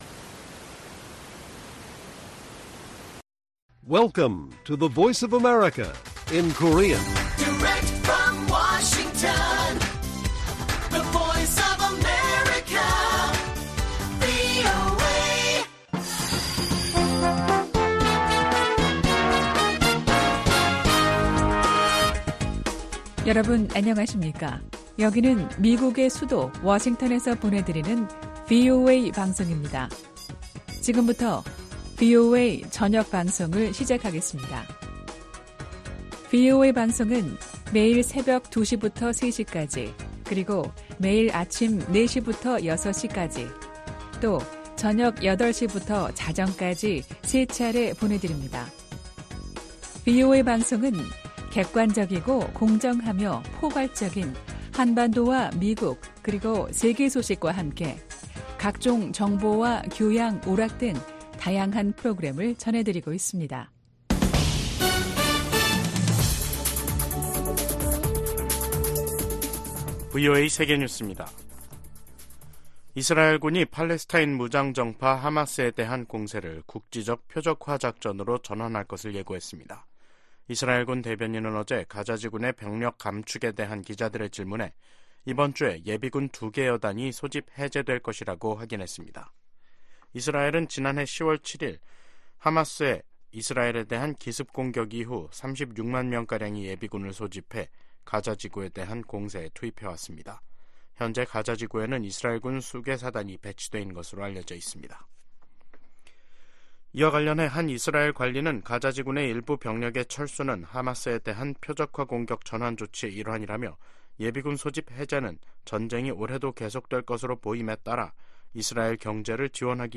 VOA 한국어 간판 뉴스 프로그램 '뉴스 투데이', 2024년 1월 2일 1부 방송입니다. 미 국무부가 김정은 북한 국무위원장의 추가 위성 발사 예고에 대륙간탄도미사일(ICBM) 개발과 다름없는 것이라는 입장을 밝혔습니다. 한국이 2년간의 유엔 안전보장이사회 비상임이사국 활동을 시작했습니다. 북한과 러시아가 미국의 금융 제재망을 우회해 무기 거래를 지속할 우려가 있다고 미국 전문가들이 지적했습니다.